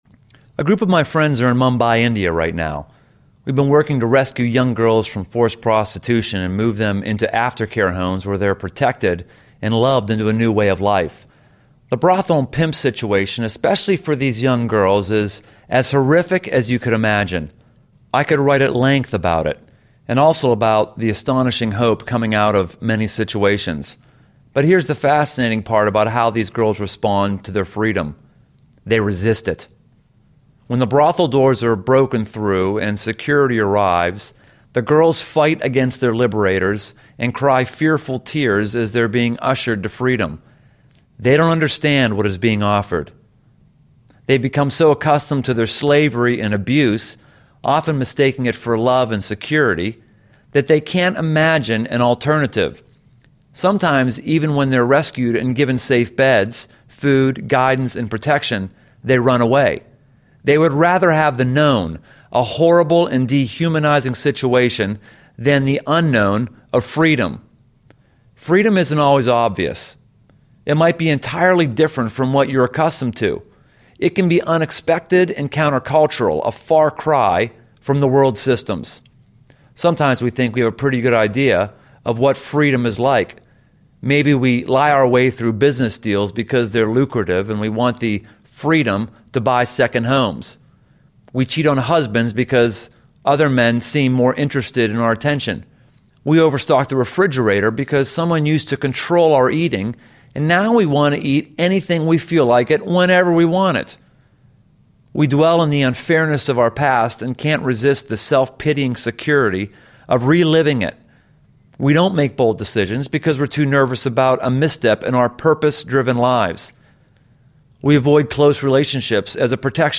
Free Book Audiobook
5.7 Hrs. – Unabridged